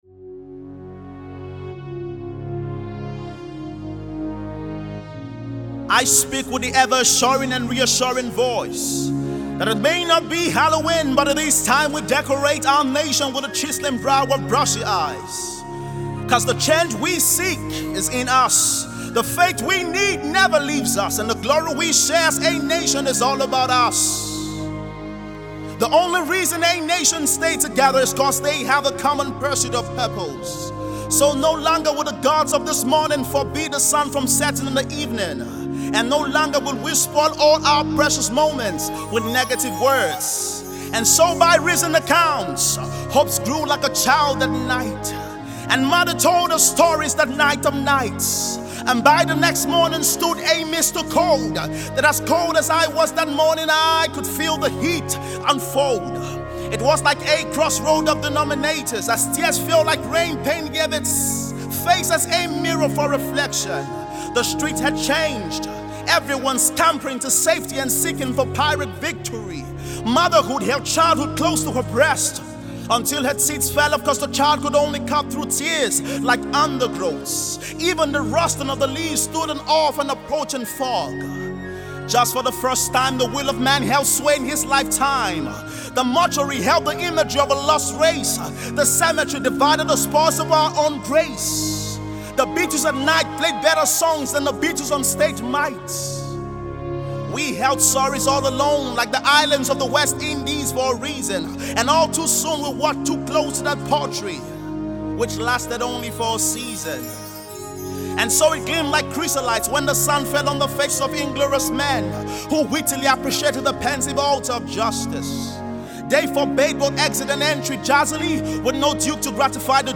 [Poem]
heart felt and motivational poem